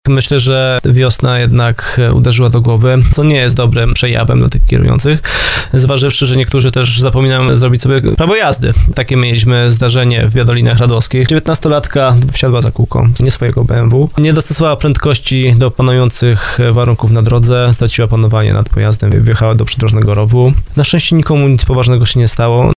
komentując sprawę w porannym programie Pomagamy i Chronimy na antenie RDN Małopolska